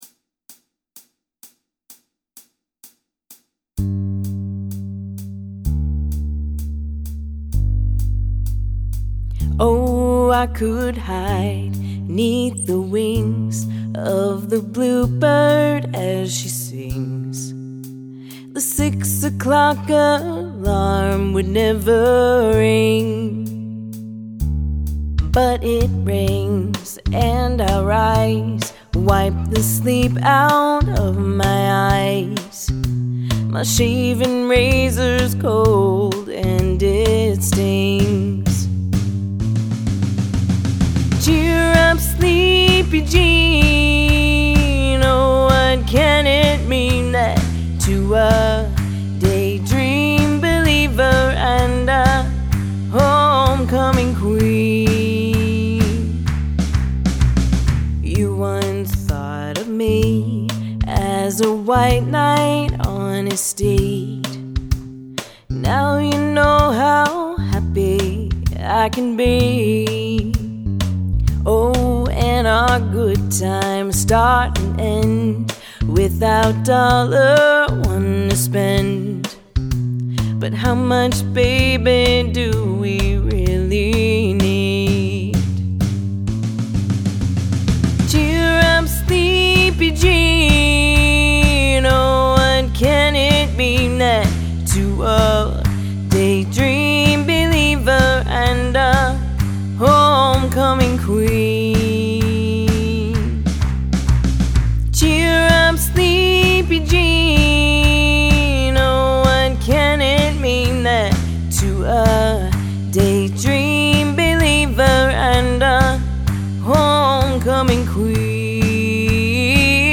jam track for you.